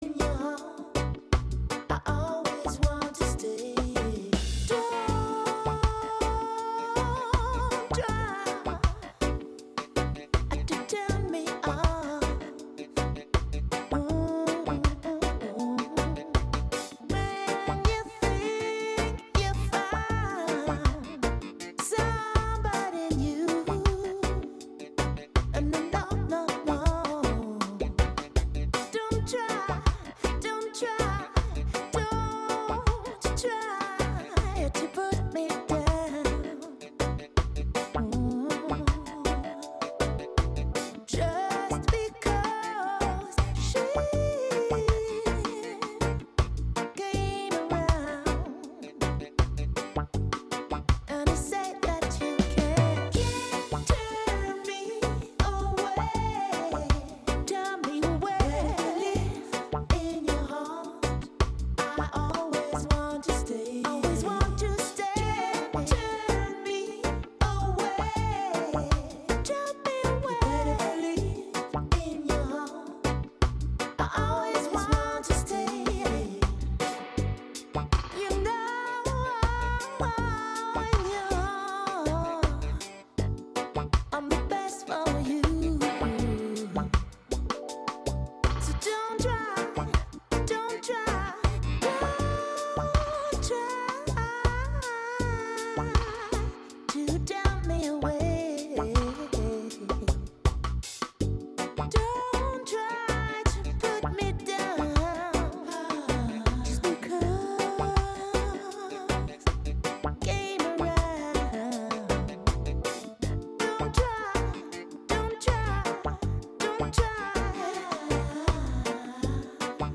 New and newly unearthed jams from all over to put you in a dancing mood.